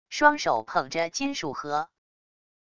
双手捧着金属盒wav音频